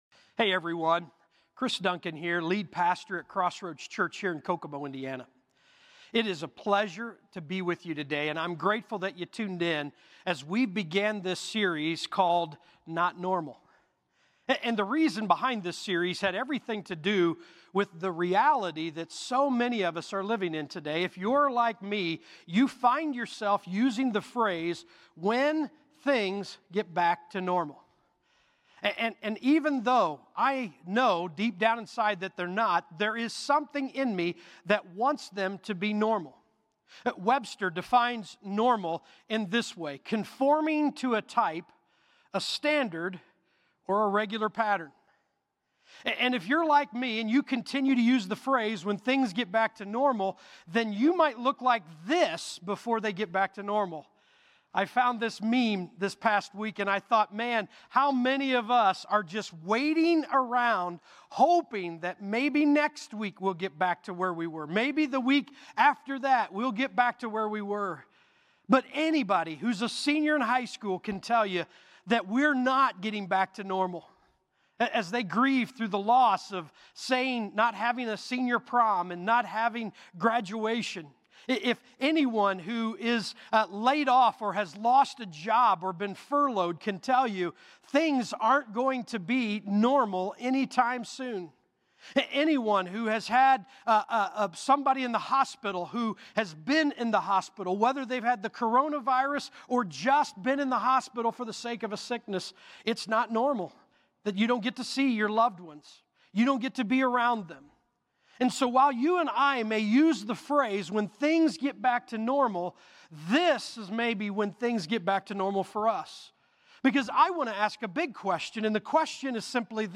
Crossroads Community Church - Audio Sermons 2020-04-19 - Change Play Episode Pause Episode Mute/Unmute Episode Rewind 10 Seconds 1x Fast Forward 10 seconds 00:00 / 32:23 Subscribe Share RSS Feed Share Link Embed